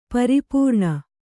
♪ pari pūṇa